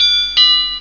snd_10004_Door Bell.wav